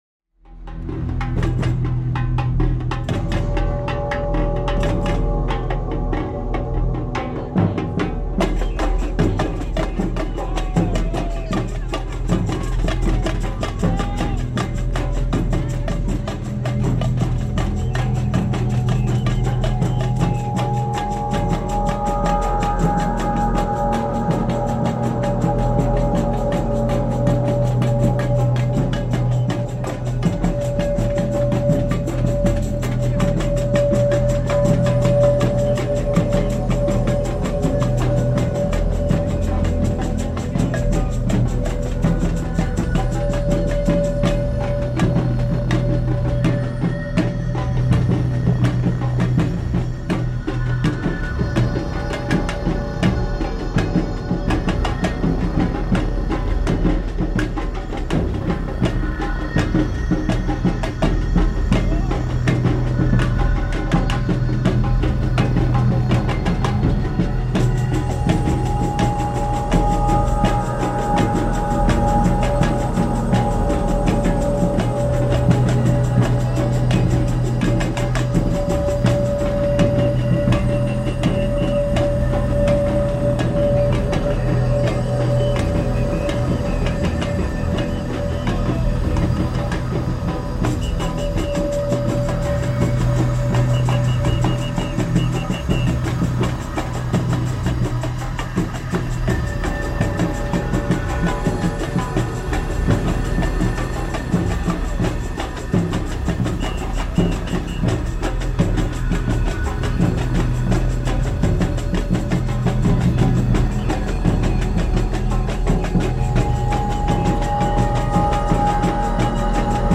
at Ferrara Buskers Festival reimagined